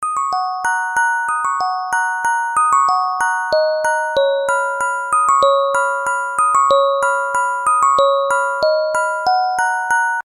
以前SH-51で作成したmmfファイルをmp3ファイルに録音し直しました。
再生する機種により、音は、多少異なって聞こえます。